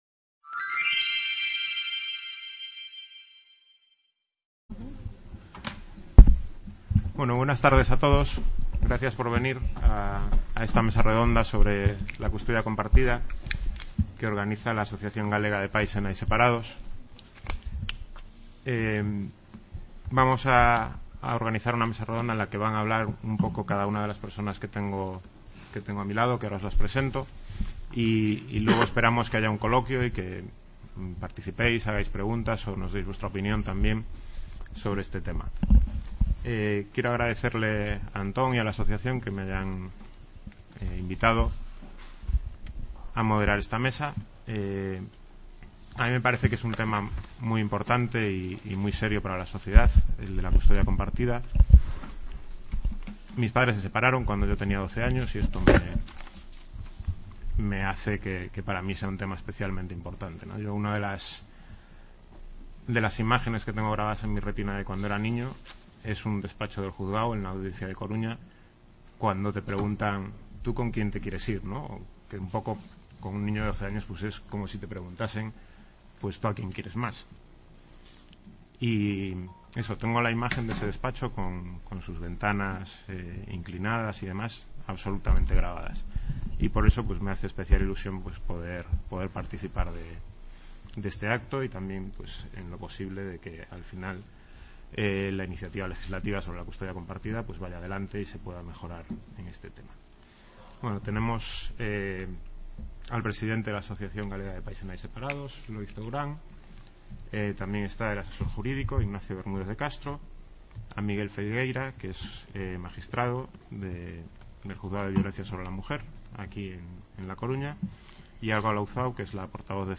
Mesa redonda.